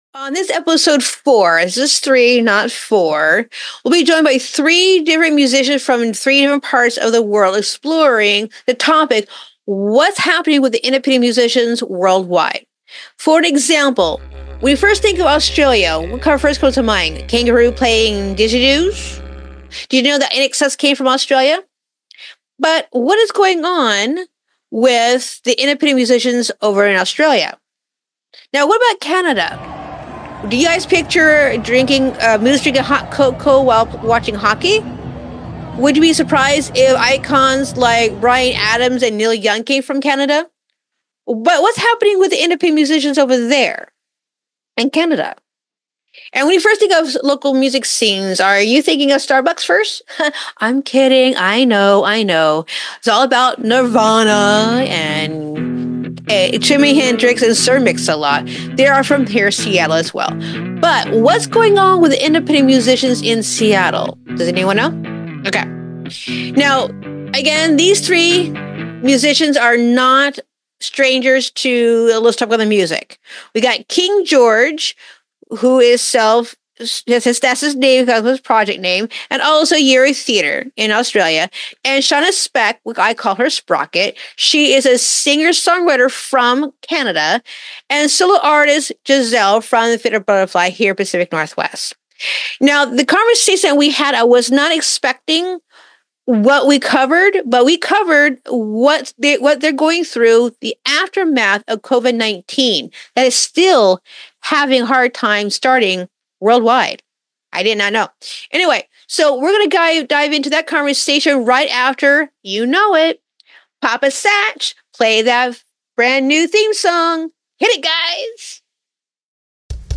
Lets Talk About The Music, Talk Show / LTATM Ep 4 – Local Music in a Post-COVID World